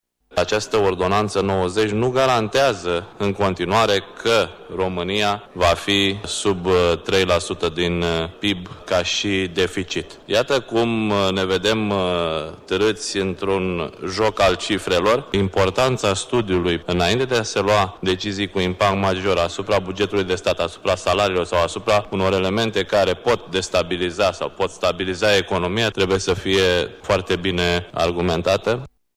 Opoziţia citică măsurile prevăzute în ordonanţă. Liderul senatorilor PMP, Dorin Bădulescu: